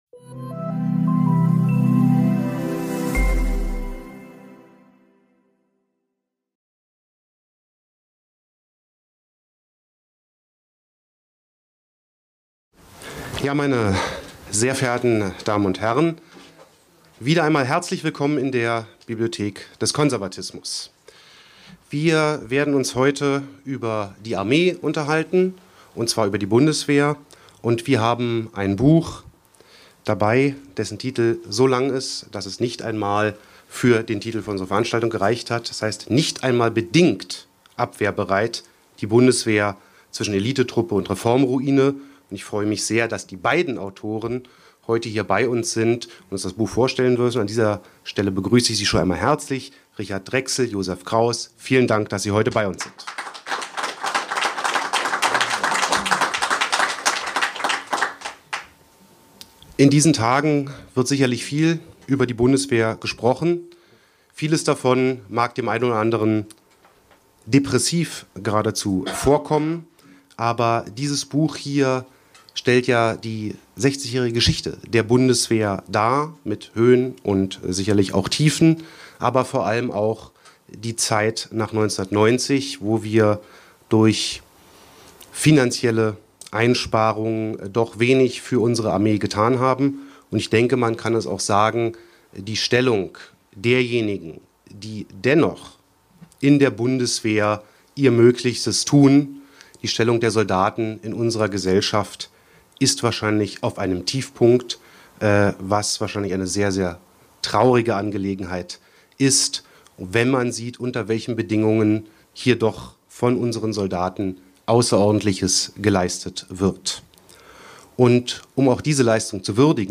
Die beiden Referenten zählten vier Problempunkte auf, welche die Bundeswehr in die heutige Misere geführt hätten.